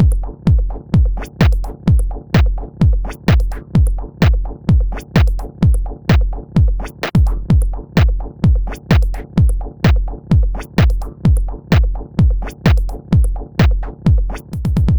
• Rhythm Electro Drum Machine House Minimal 2 - Gm - 128.wav
Rhythm_Electro_Drum_Machine_House_Minimal__2_-_Gm_-_128_ZNk.wav